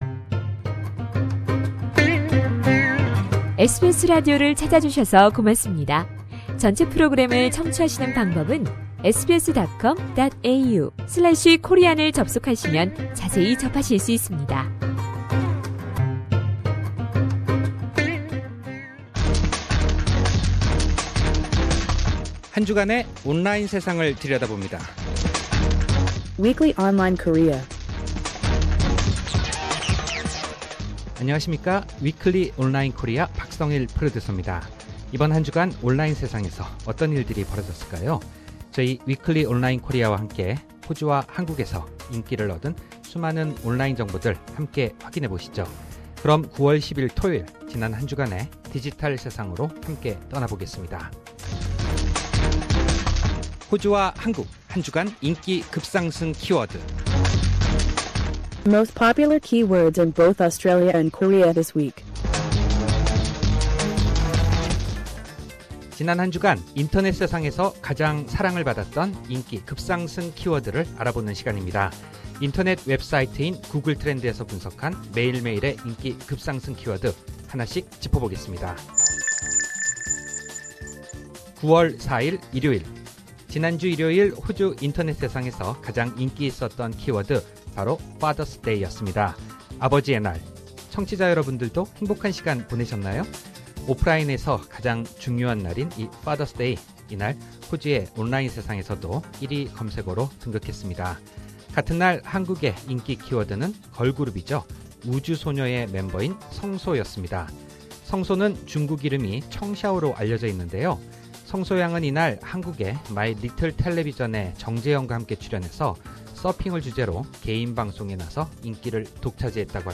상단의 다시 듣기 (Podcast)를 클릭하시면 라디오 방송을 다시 들으실 수 있습니다. 매일매일 호주와 한국의 인기 급상승 키워드를 알아보고, 해당 키워드가 1위를 차지하게 된 자세한 설명을 이야기해 드립니다.